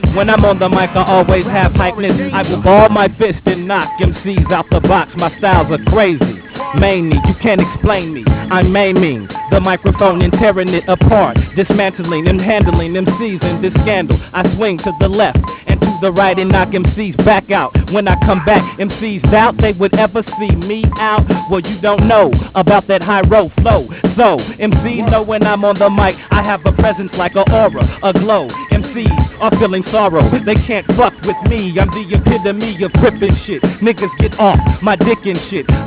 Freestyle